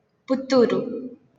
Puttur (Pronunciation: